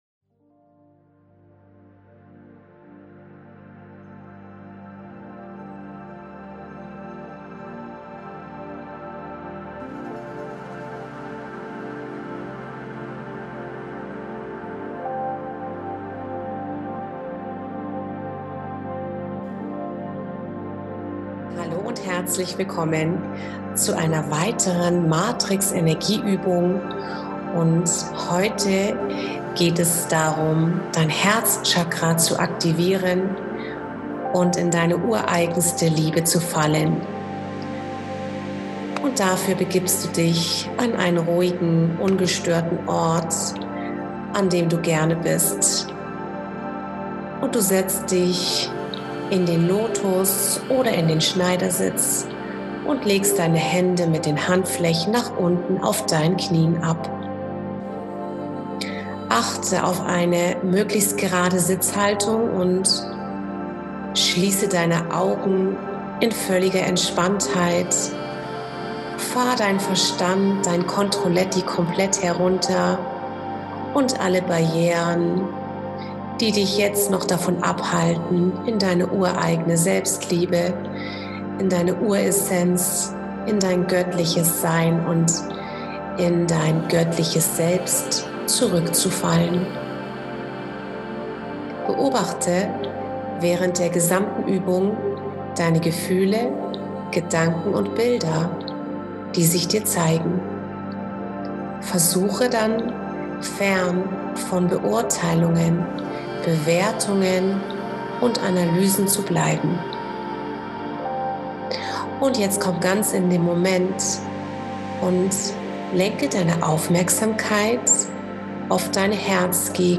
Viel Spaß mit der Meditation